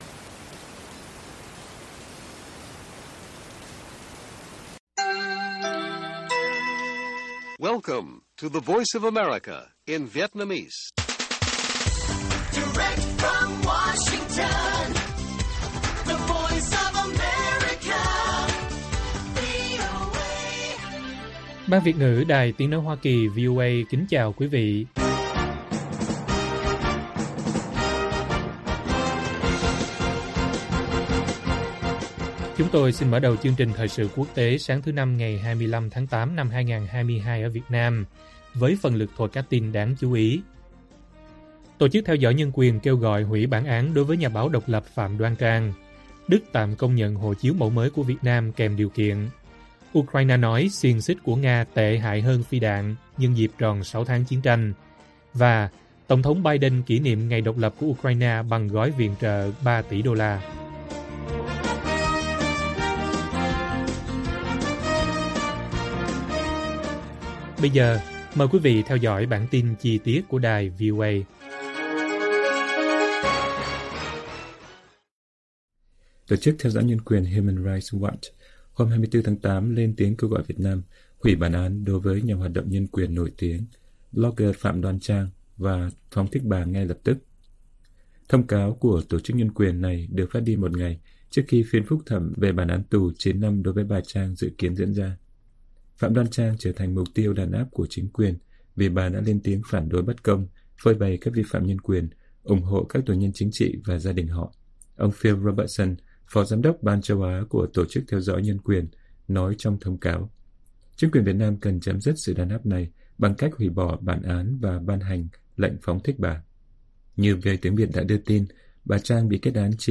Ukraine kỉ niệm Ngày Độc lập đúng ngày tròn 6 tháng chiến tranh - Bản tin VOA